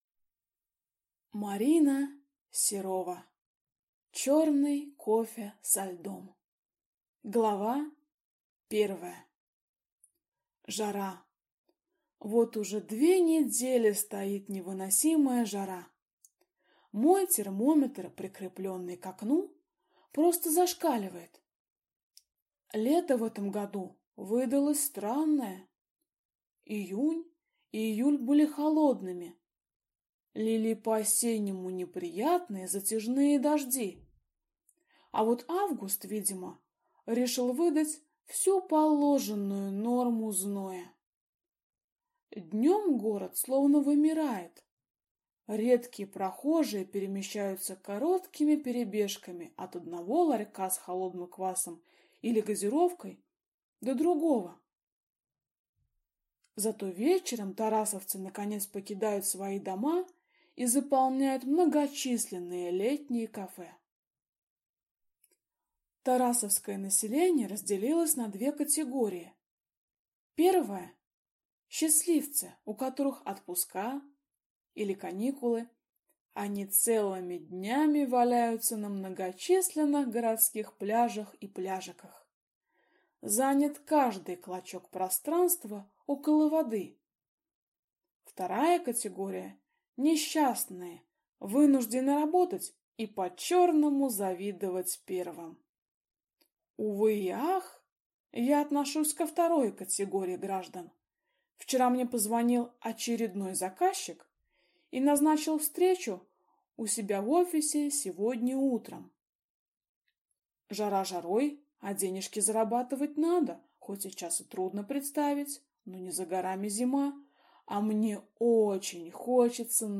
Аудиокнига Черный кофе со льдом | Библиотека аудиокниг